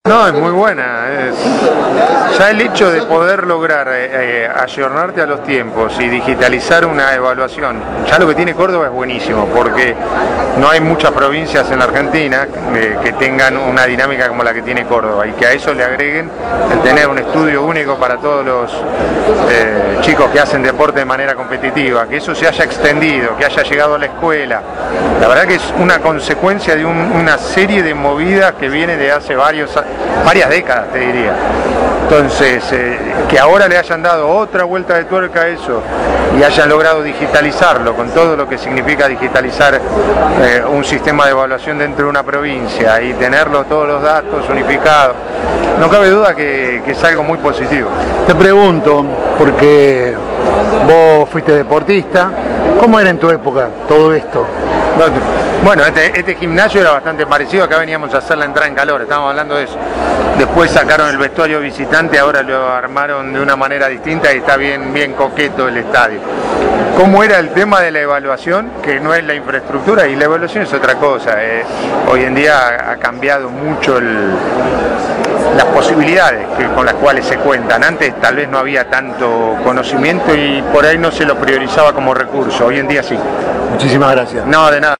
Córdoba y la modernización de la salud deportiva. Voces de los protagonistas de una Jornada Histórica en el Polo Deportivo Kempes.